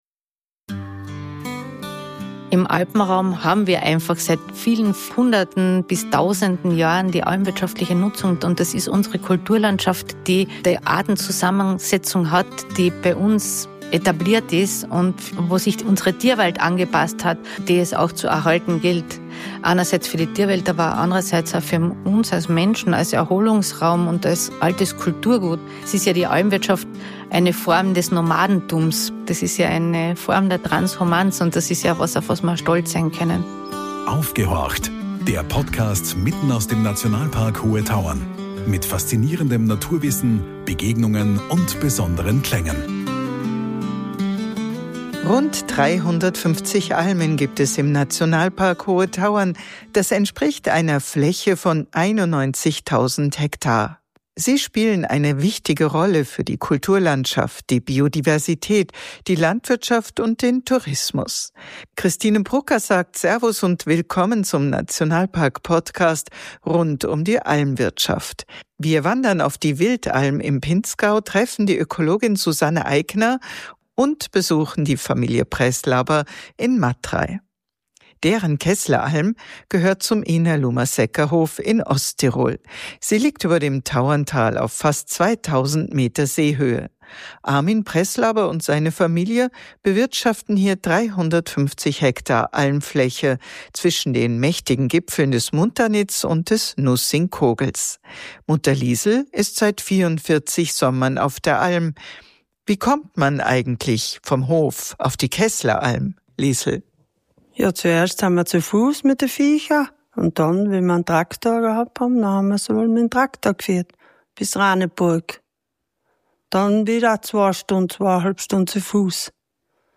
Im Gespräch erzählen Almbäuerinnen und -bauern von harter Arbeit, Familienzusammenhalt, moderner Energieversorgung, Direktvermarktung und den Herausforderungen durch Wetterextreme und Klimawandel.